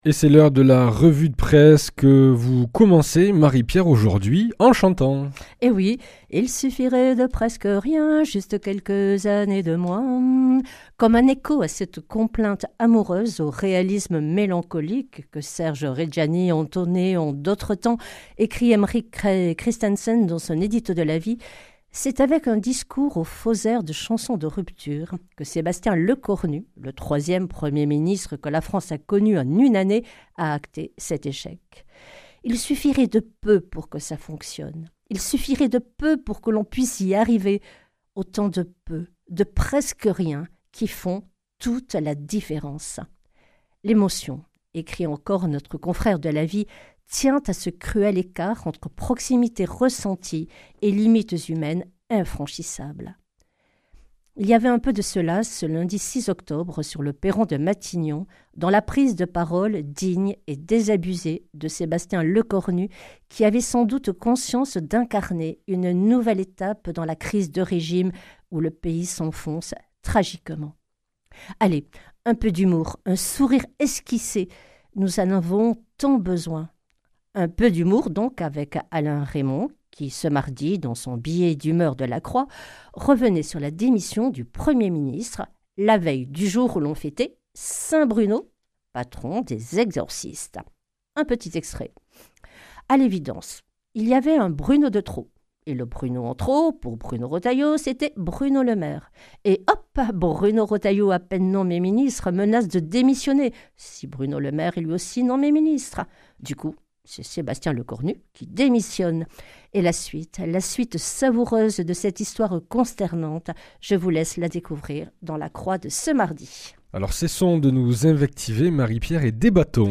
vendredi 10 octobre 2025 La revue de presse chrétienne Durée 5 min
Une émission présentée par
Journaliste